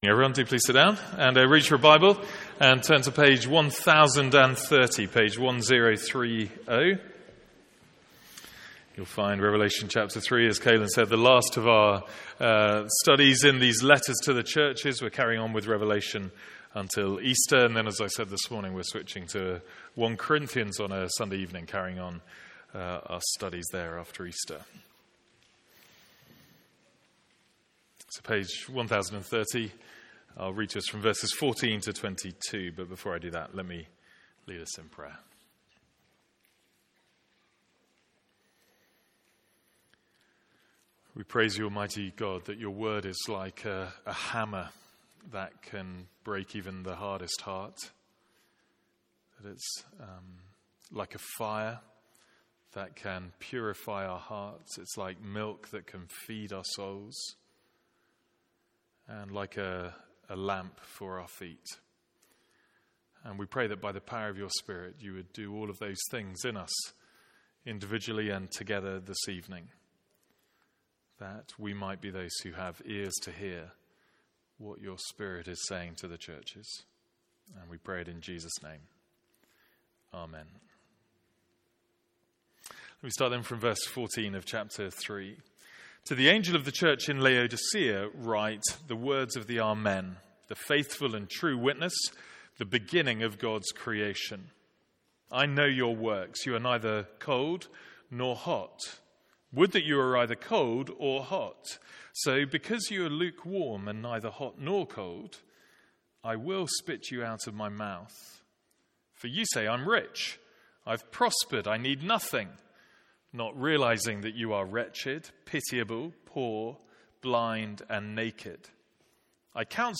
Sermons | St Andrews Free Church
From our evening service in Revelation.